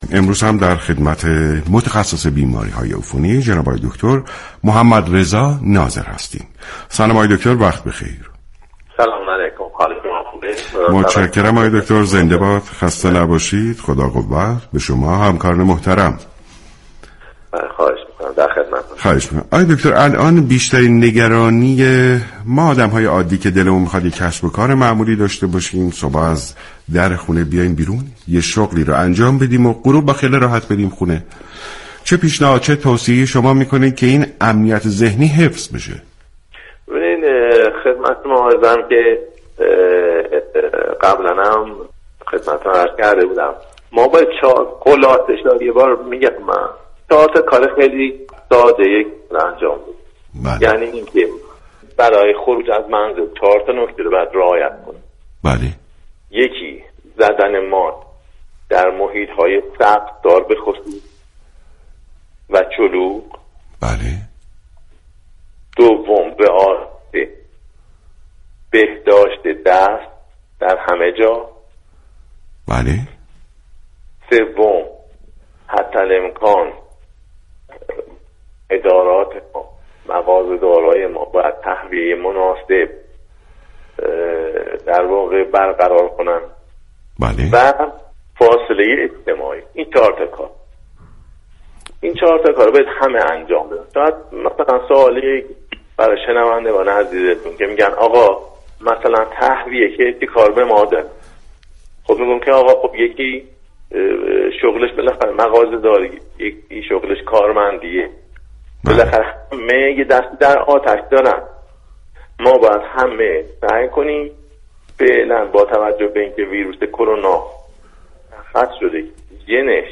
شما می توانید از طریق فایل صوتی ذیل شنونده بخشی از برنامه سلامت باشیم رادیو ورزش كه شامل صحبت های این متخصص بیماری های عفونی درباره كرونا است؛ باشید.